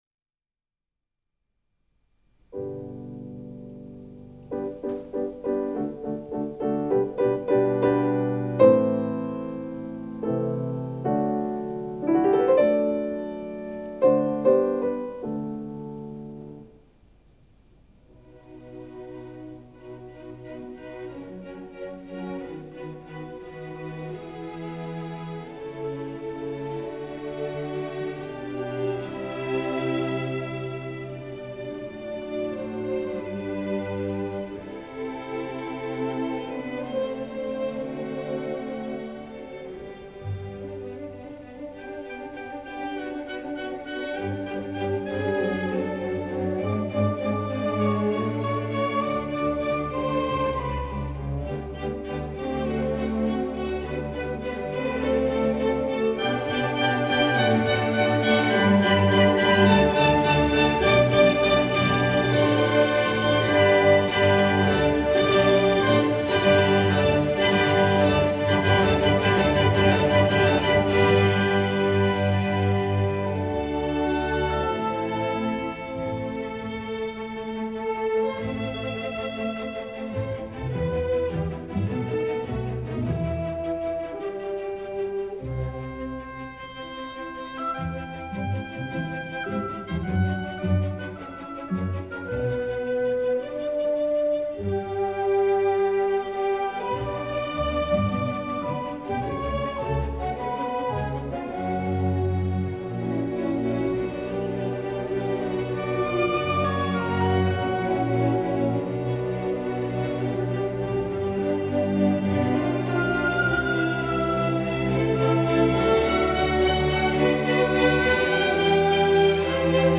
Rarities tonight from the Archives of Swiss Radio. Legendary pianist Wilhelm Backhaus in concert with Ferenc Fricsay and Orchestre de la Suisse Romande, recorded by RTS on May 24, 1961.